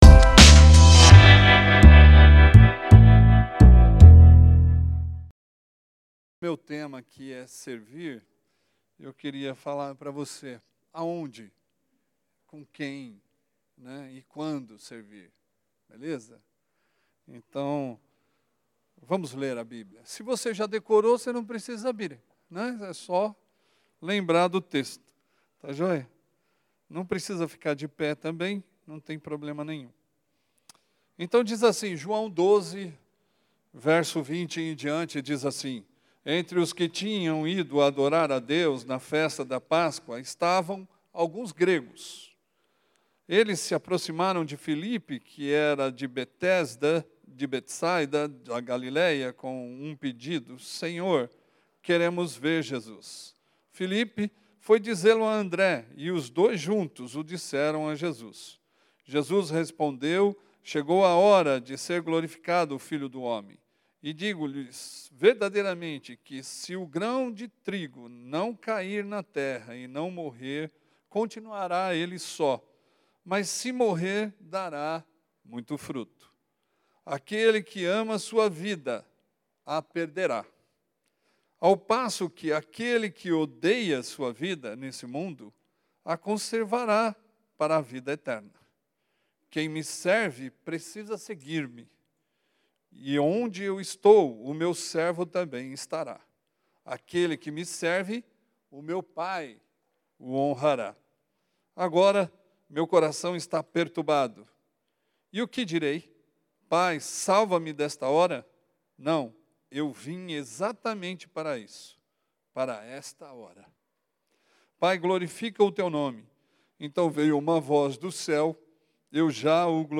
Primeira mensagem de 3 da nossa Conferência Missionária 2021.